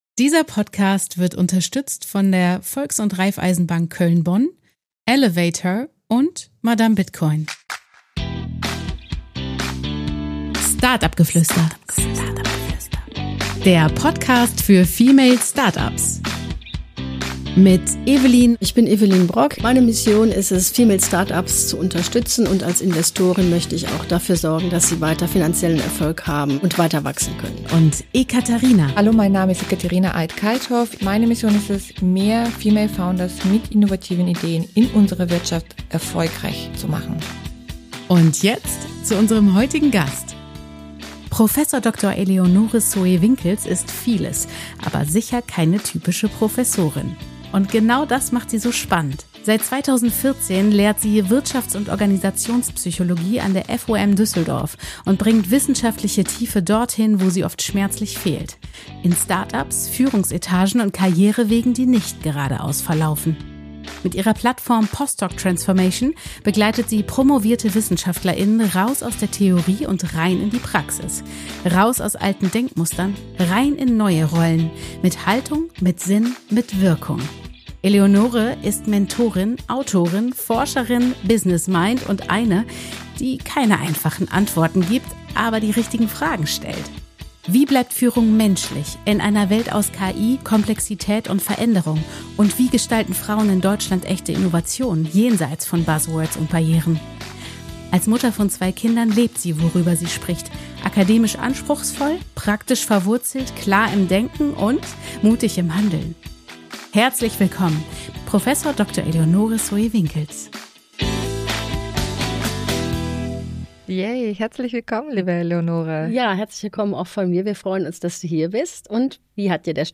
Wir sprechen über Teamdynamik, digitale Sichtbarkeit und darüber, wie echte Innovation in Deutschland weiblicher, diverser und menschlicher werden kann. Ein Podcast über mutige Wege, kluge Fragen und Führung mit Substanz.